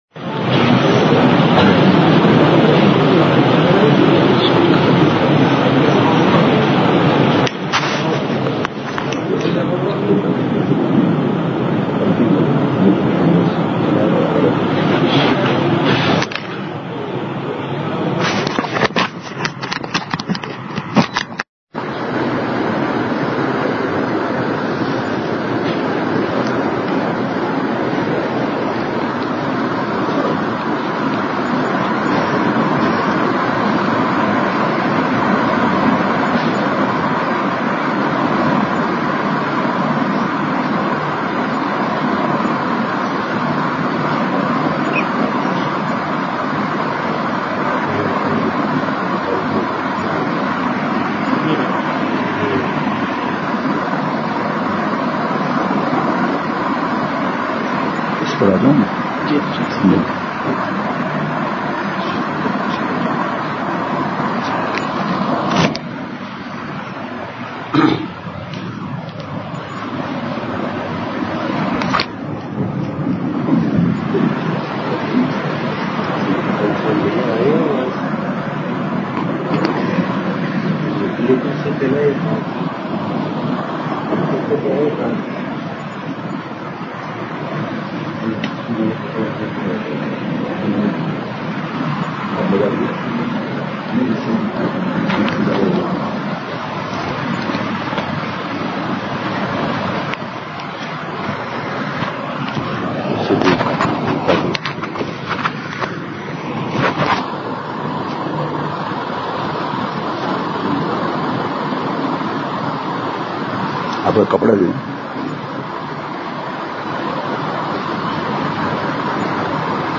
بمقام: مسجد طوبی پشین